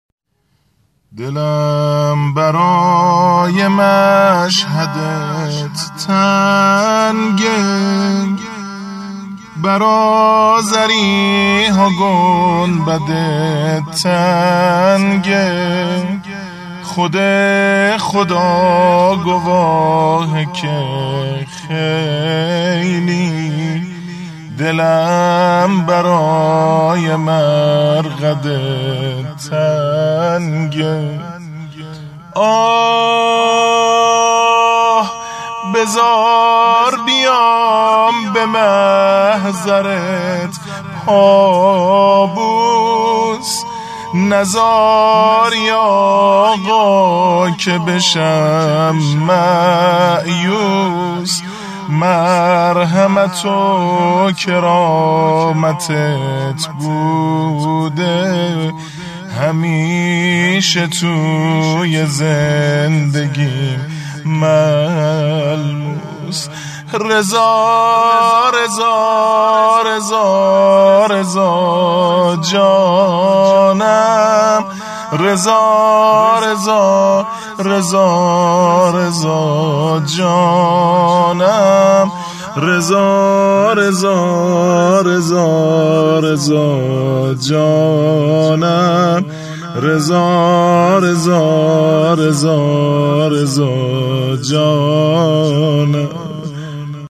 شور ، زمزمه - - -